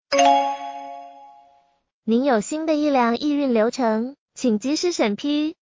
ring.mp3